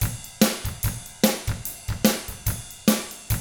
146ROCK T2-L.wav